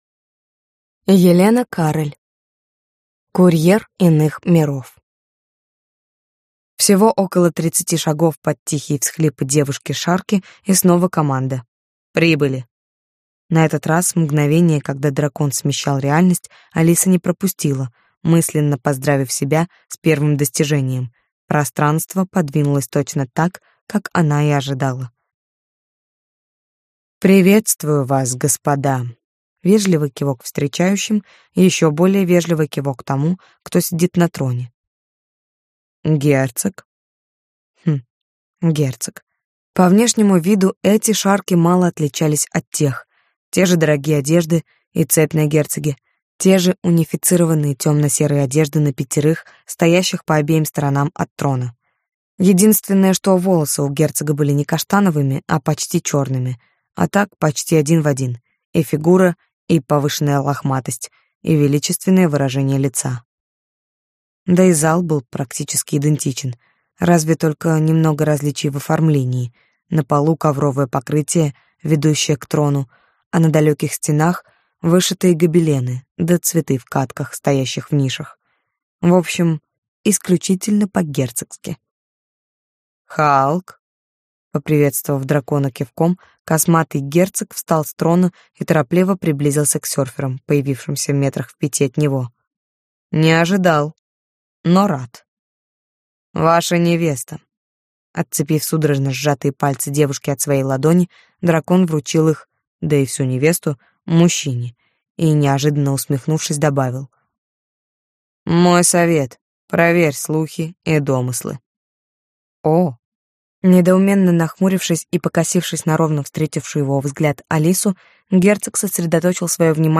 Аудиокнига Курьер Иных миров | Библиотека аудиокниг